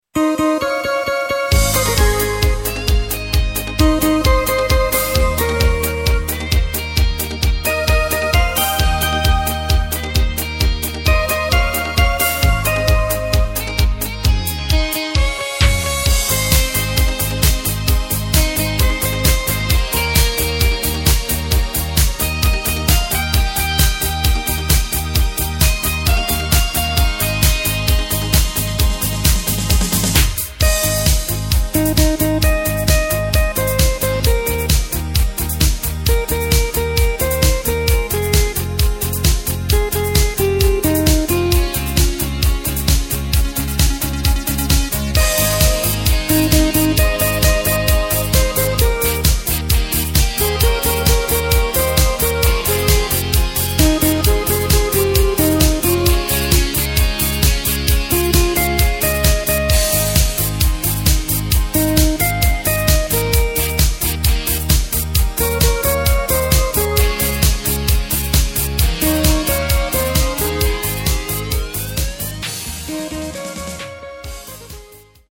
Takt:          4/4
Tempo:         132.00
Tonart:            Ab
Discofox aus dem Jahr 2016!